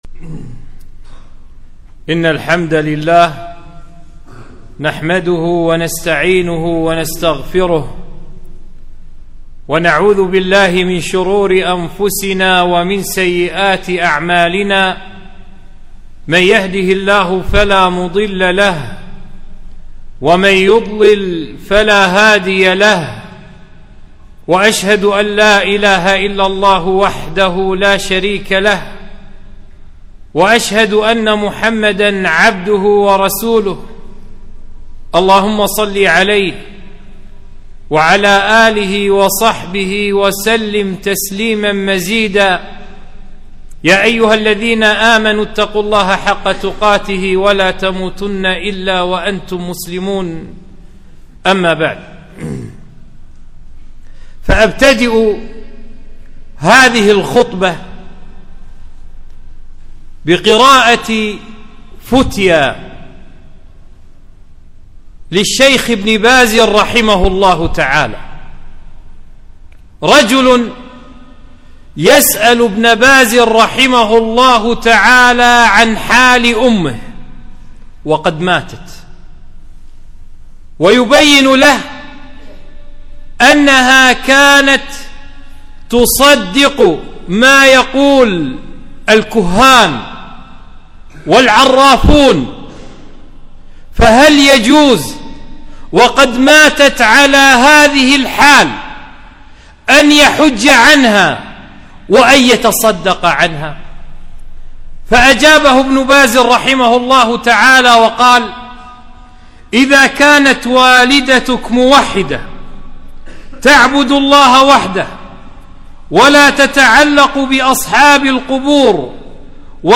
خطبة - ( لا يعلم الغيب إلا الله )